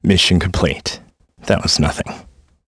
Riheet-Vox_Victory.wav